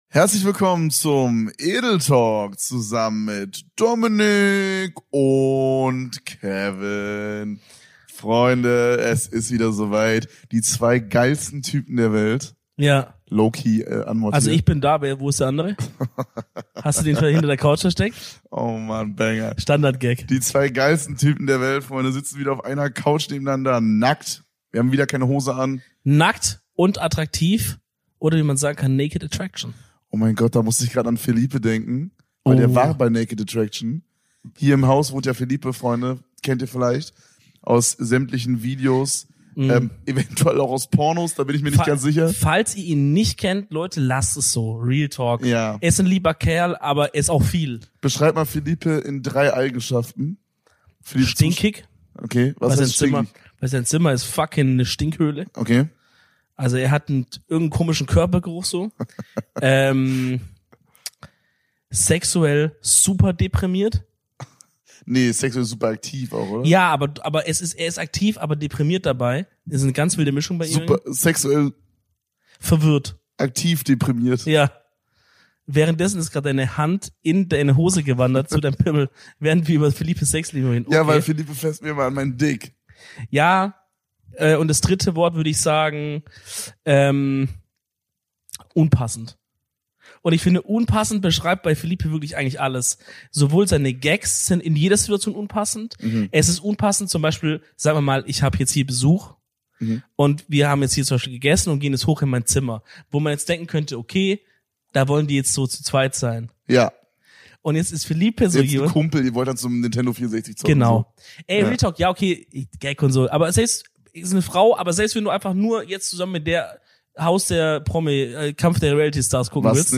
Wieder eine Aufnahme um 2 Uhr nachts, wieder tiefsinnige Gespräche, wieder eine Stunde Premium Content.